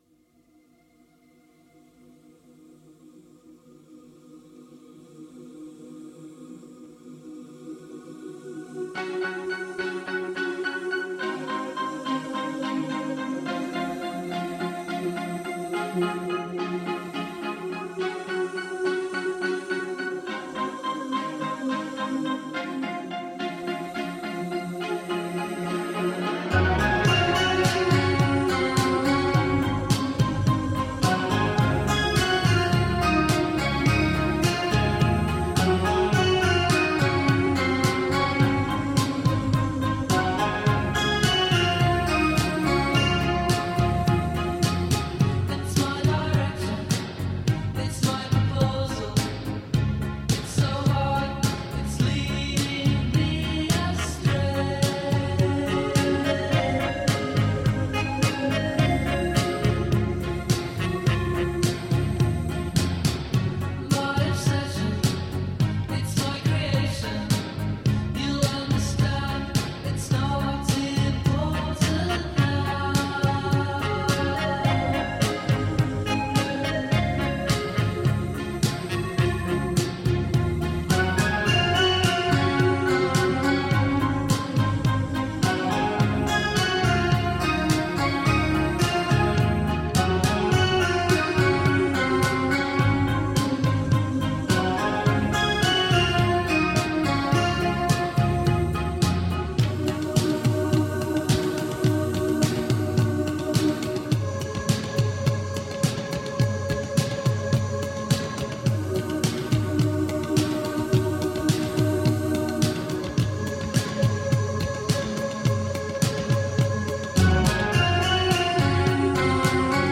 Electronic Hip Hop Indie Pop Rock